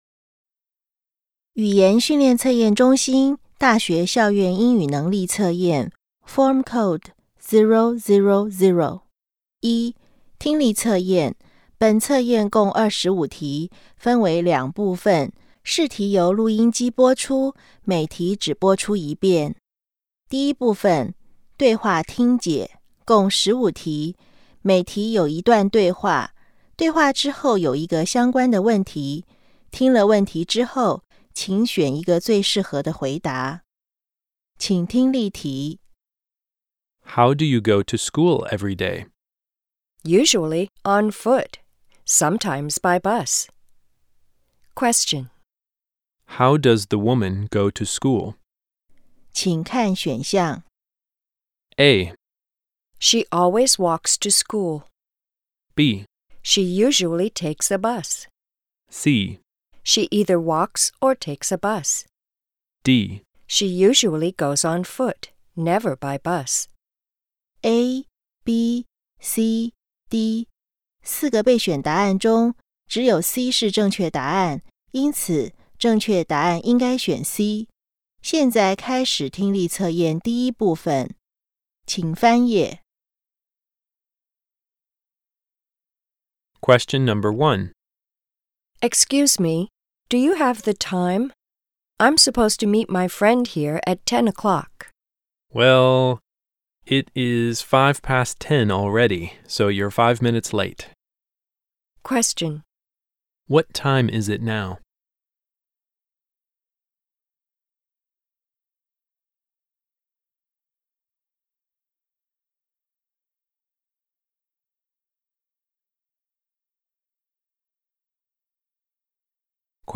第一級聽力測驗錄音檔.mp3